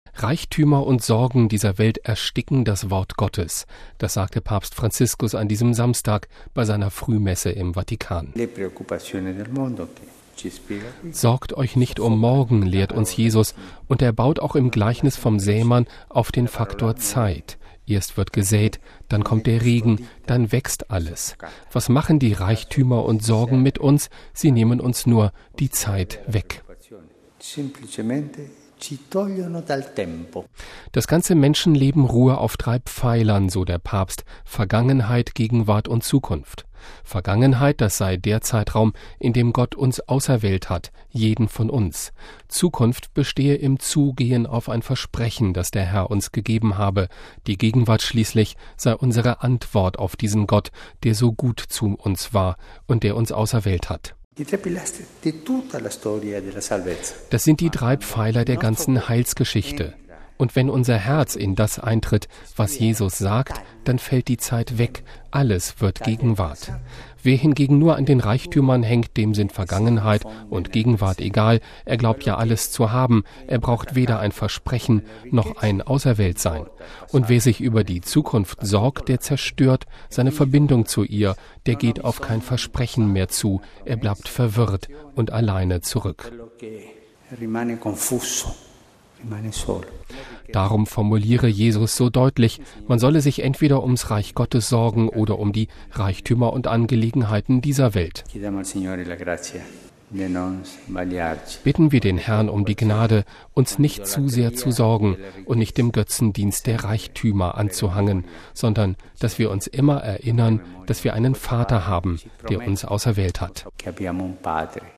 Papstpredigt: „Nicht am Reichtum hängen“
Das sagte Papst Franziskus an diesem Samstag bei seiner Frühmesse im Vatikan.